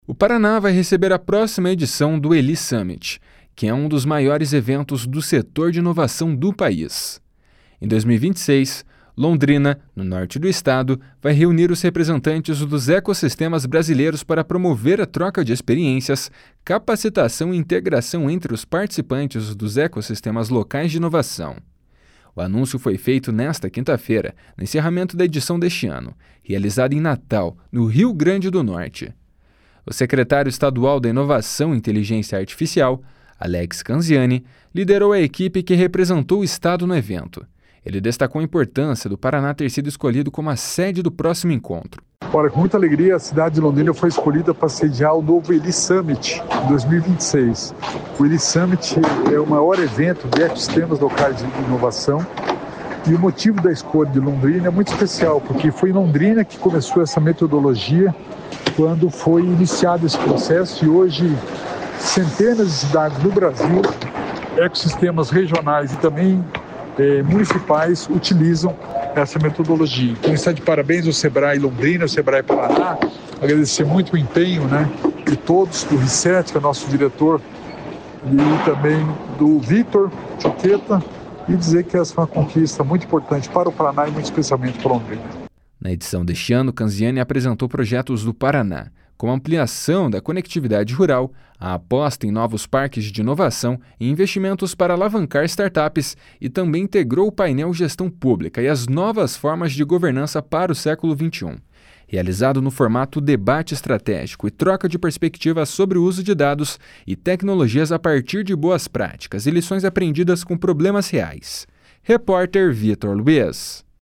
// SONORA ALEX CANZIANI //